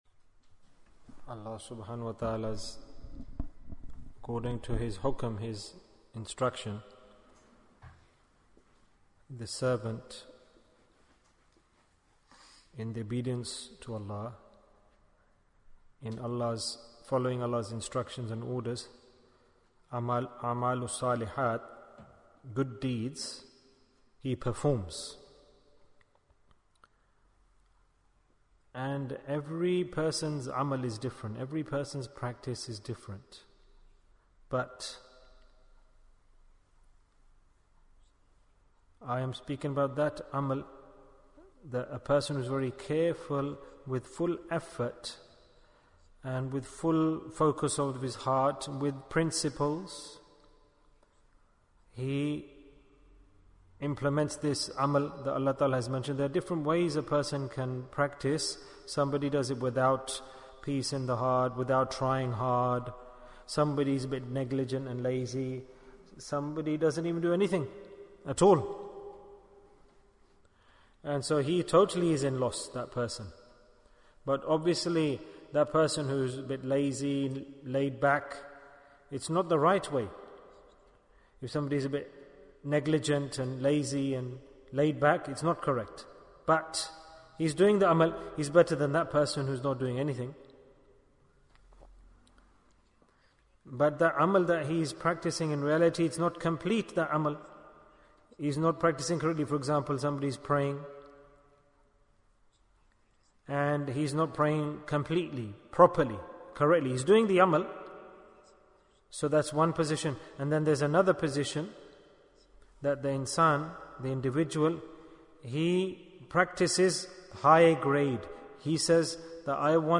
The Deed by Which Ibaadah is Accepted Bayan, 23 minutes16th April, 2021